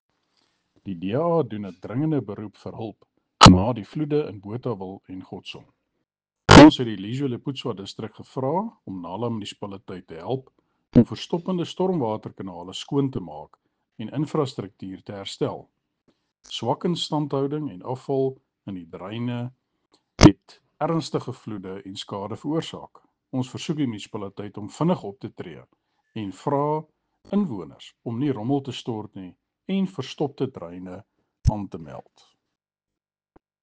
Afrikaans soundbite by Cllr Andre Kruger.